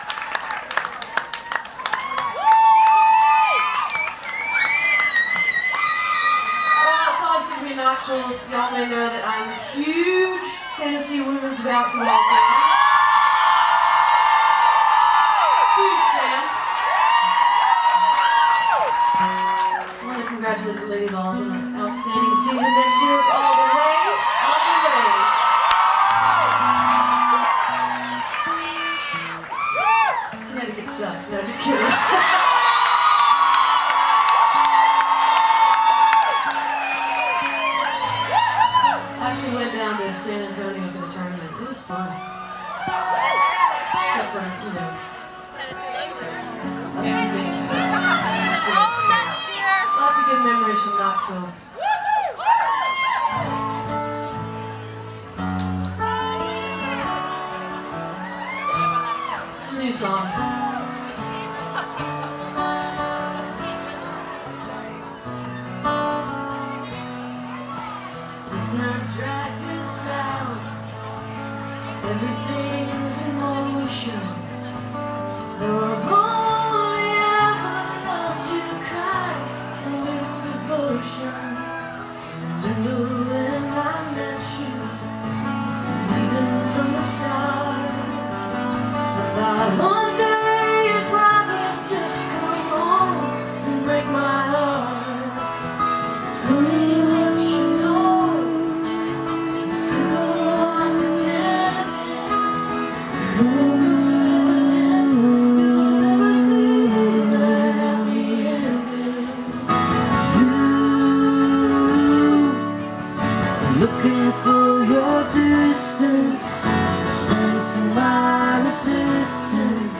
(acoustic duo)